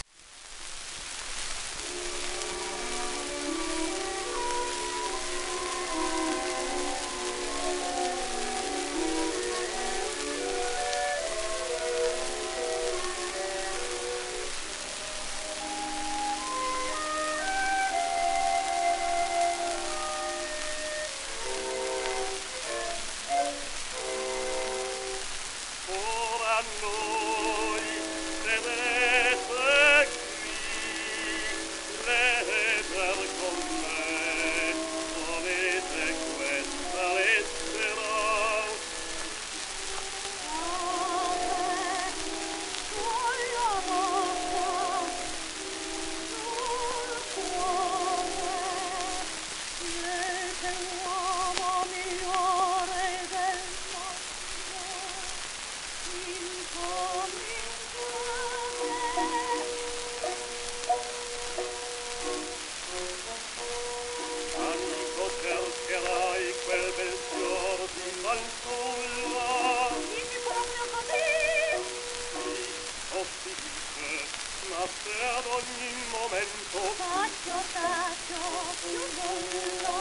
、オーケストラ
12インチ片面盤
盤質A-/B+ * キズ、小キズ,サーフェイスノイズ
1909年頃の録音